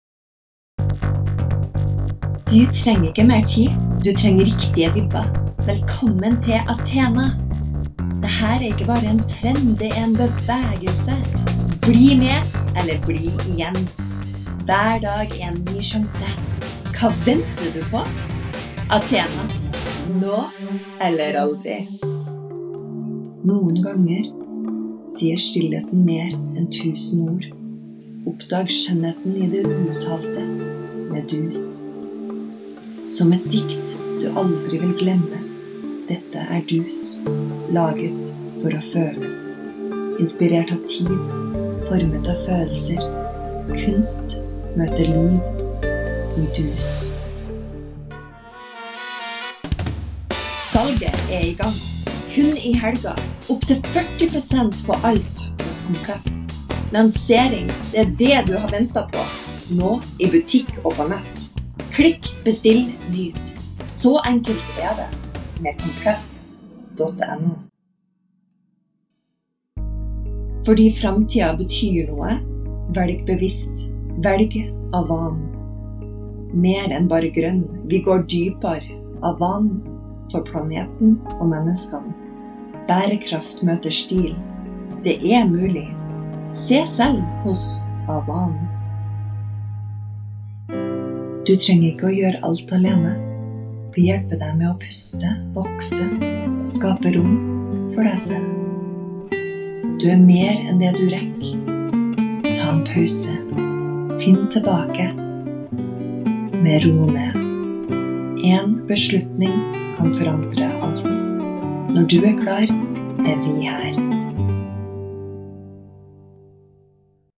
-I have finally set up a home studio with several microphones.
-My voice range is catagorized from 20s-50s female voice.
Demo reels/ Voiceover samples
Accent Mix
Southern accent